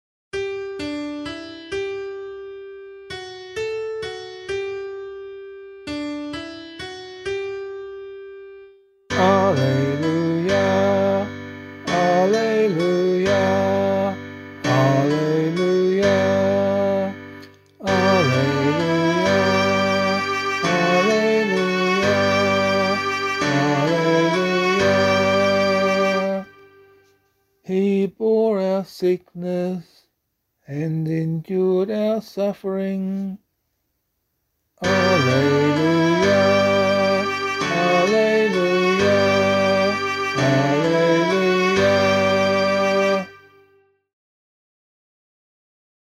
039 Ordinary Time 5 Gospel B [LiturgyShare F - Oz] - vocal.mp3